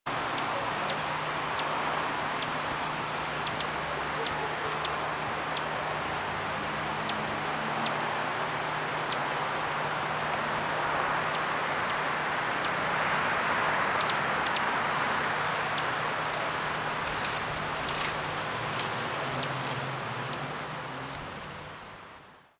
Rufous Hummingbird an uncommon western visitor!
I was able to get a recording of its call as well.
Rufous-Hummingbird-UF-IFAS-Extension-Redlands.wav